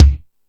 Lotsa Kicks(49).wav